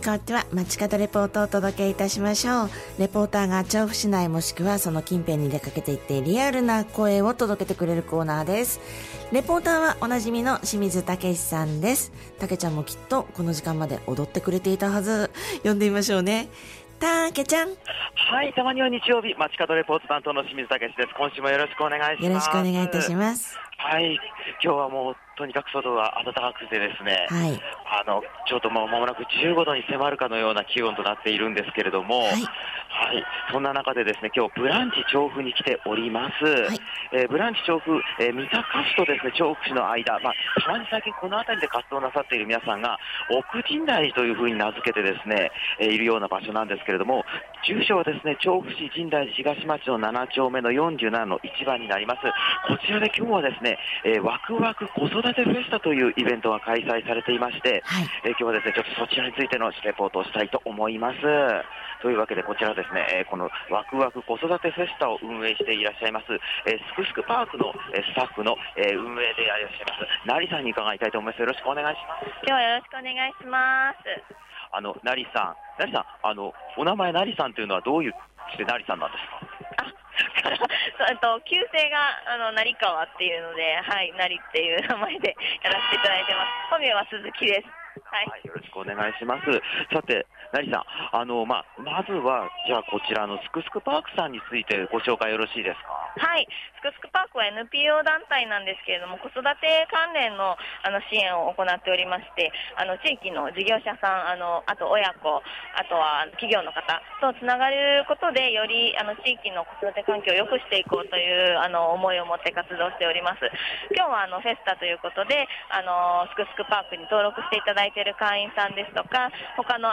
今週も青空の下からお届けした本日の街角レポートは、 ブランチ調布よりすくすくぱぁくさんが主催「ワクワク子育てフェスタ」の会場からのレポートです！！